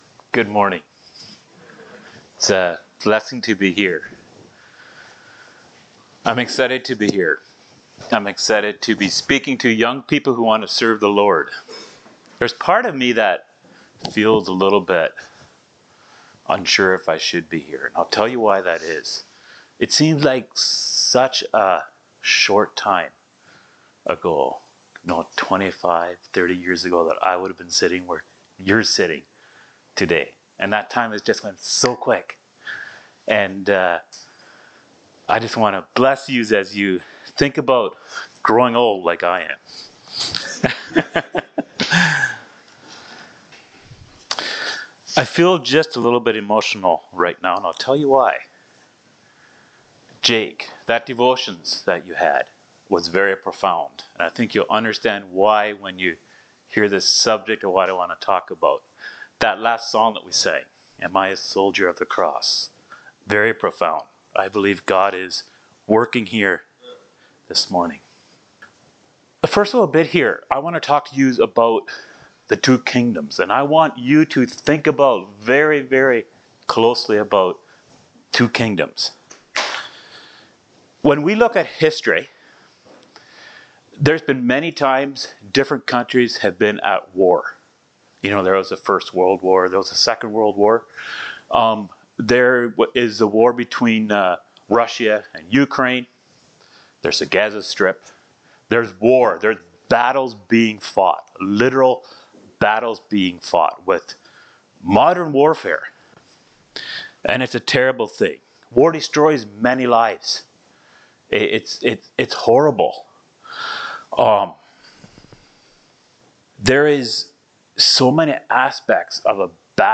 Service Type: Youth Meetings